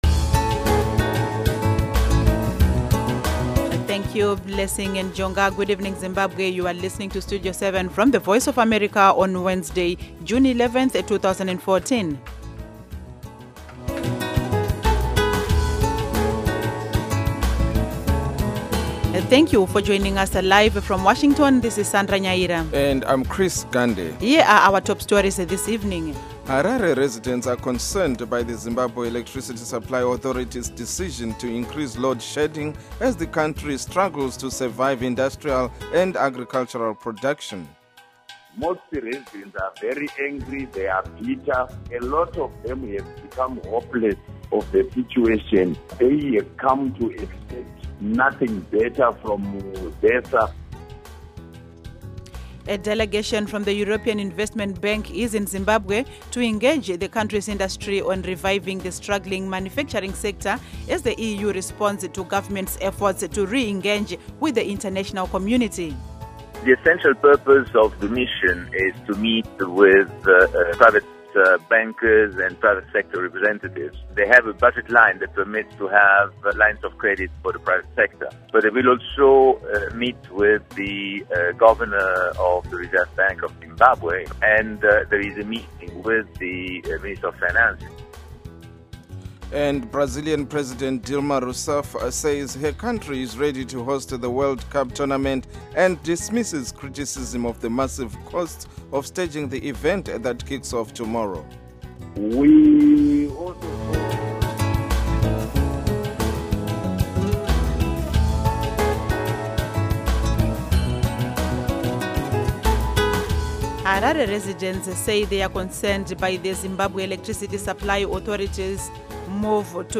Studio 7 for Zimbabwe provides comprehensive and reliable radio news seven days a week on AM, shortwave and satellite Schedule: Monday-Friday, 7:00-9:00 p.m., Saturday-Sunday, 7:00-8:00 p.m., on Intelsat 10 repeats M-F 9-11 p.m. Local Time: 7-9 p.m. UTC Time: 1700-1900 Duration: Weekdays: 2 hours; Weekends: 1 hour Listen: MP3